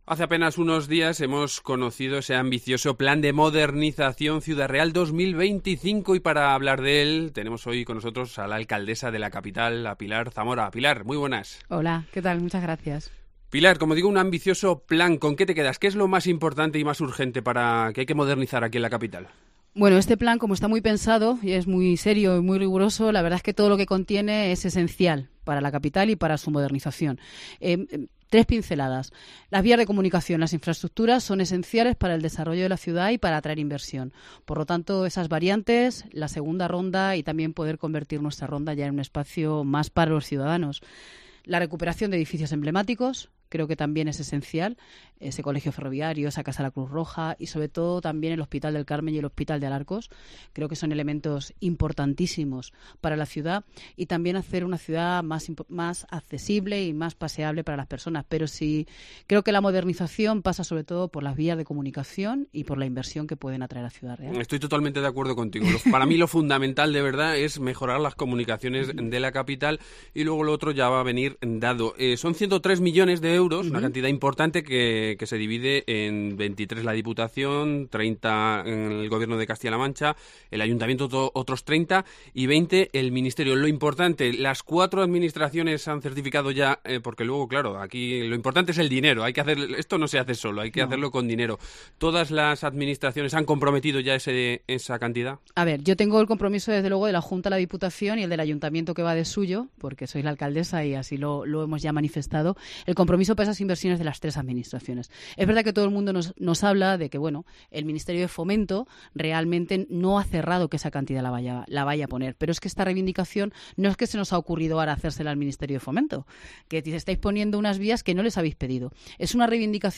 Hoy nos visita la alcaldesa de Ciudad Real, Pilar Zamora.